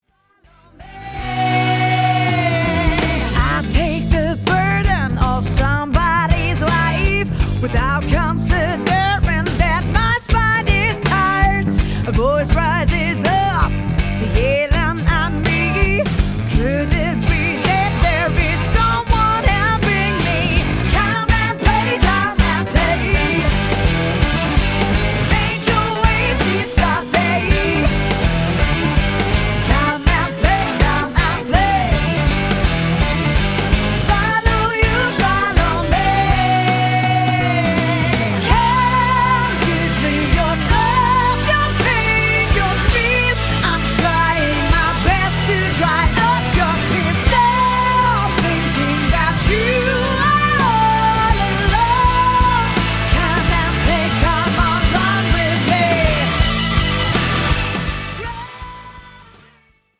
vocals
guitars, acoustic guitar
keyboards, piano
bass, background vocals
drums